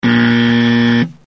Buzzer2
Tags: cartoon tv